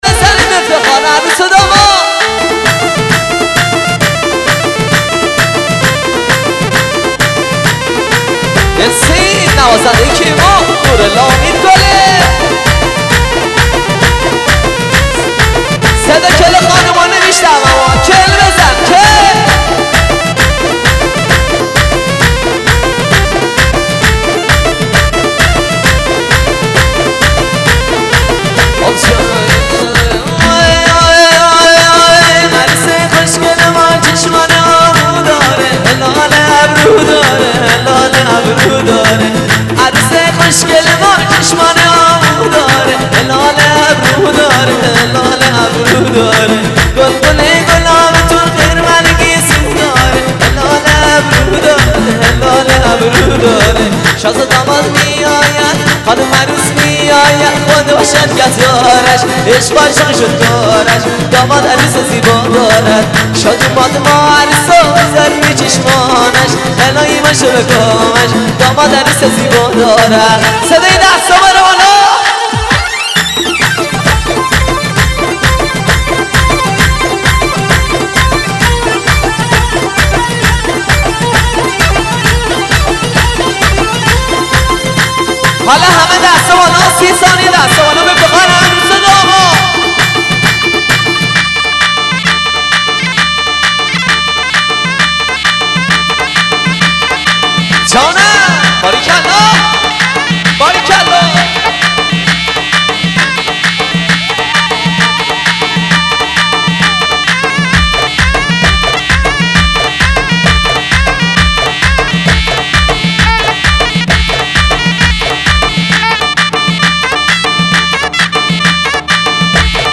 موزیک شاد